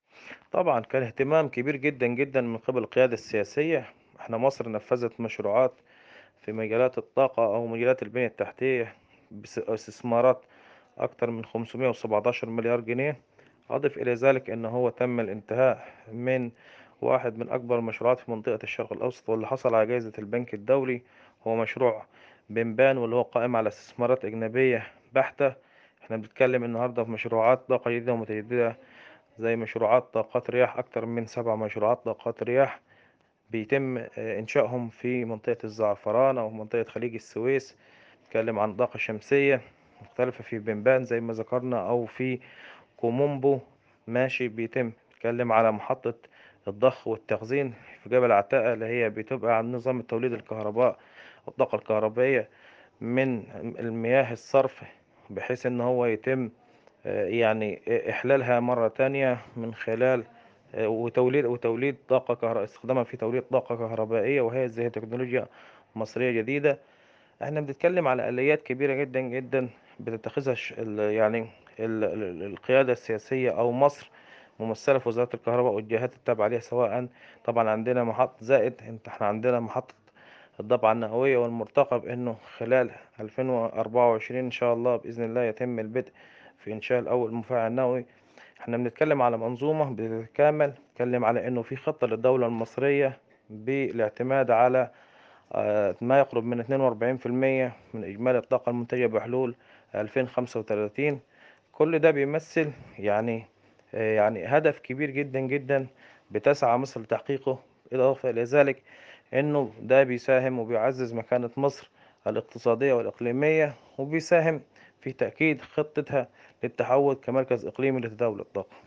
حوار
محلل اقتصادي ومتخصص في قطاع الكهرباء والطاقة